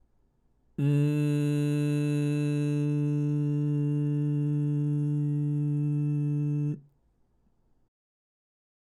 グーの声を出してたはずなのに、”音の角（ジリジリ感）”が取れていき、少しずつ”やわらかい丸みのある音”に近づいていけばチョキの練習は成功です！
※仮声帯のジリジリが入ったG(ん)⇒笑いながらCに変えていくの音声
10_kaseitai_hanasu_n.mp3